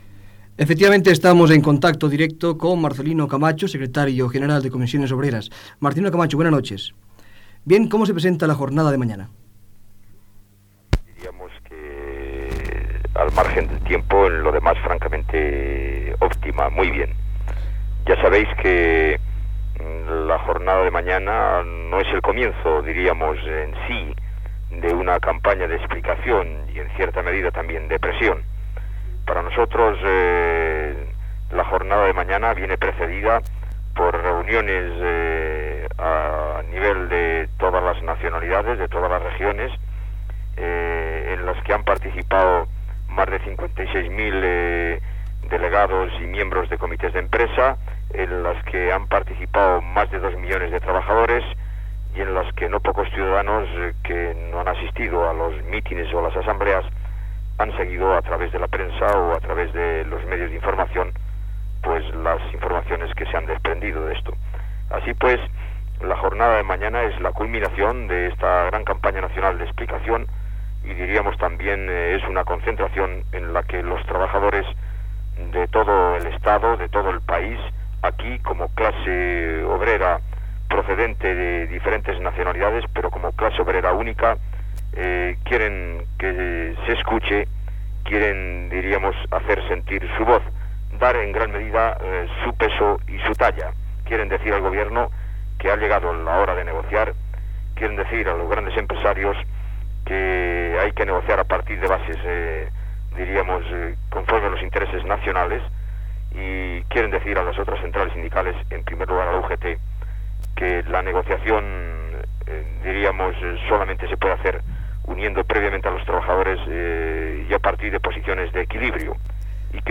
Entrevista al Secretari General de Comissions Obreres Marcelino Camacho sobre la jornada que es farà a l'endemà, a la Casa de Campo de Madrid, per protestar contra el Plan Económico del Gobierno i el Proyecto da Estatuto del Trabajador
Informatiu